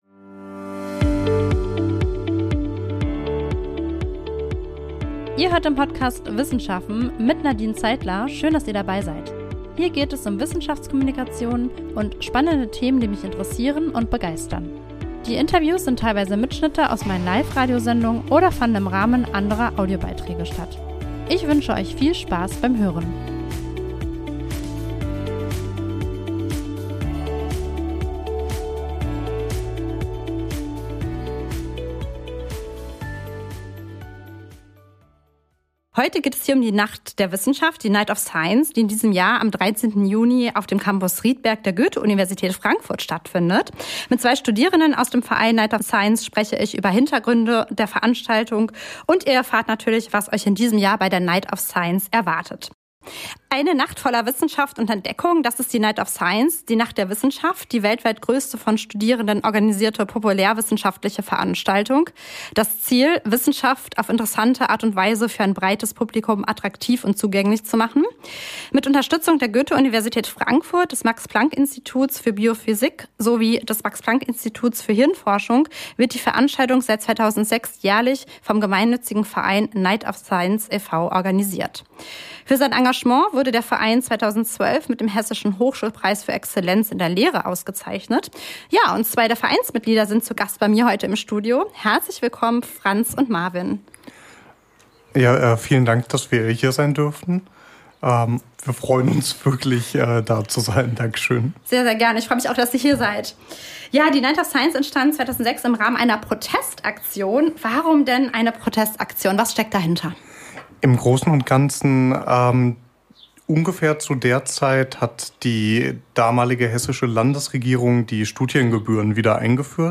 Mit zwei Vereinsmitglieder spreche ich über Hintergründe und die Night of Science 2025, die am 13. Juni von 17:00 Uhr bis 5:00 Uhr morgens auf dem naturwissenschaftlichen Campus Riedberg der Goethe-Universität Frankfurt (Max-von-Laue-Straße 9) stattfindet.